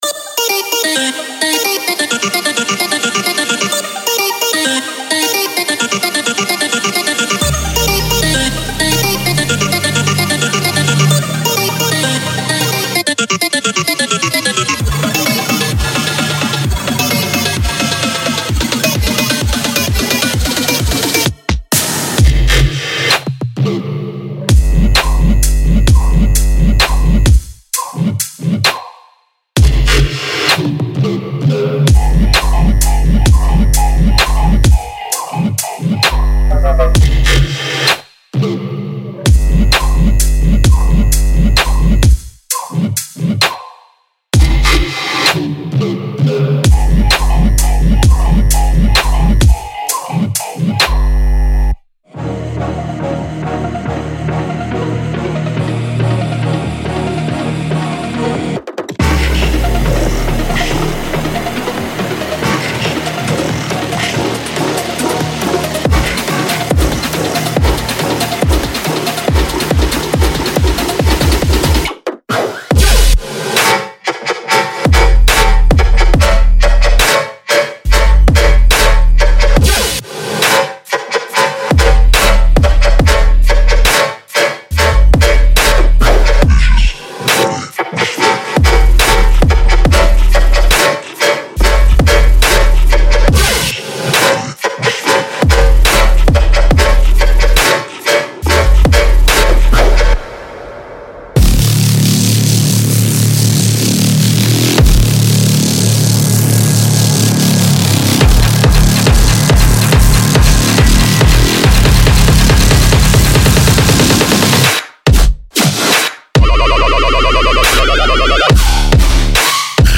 4 808循环
24低音循环
17打击乐循环
29旋律循环
8声乐短语和一枪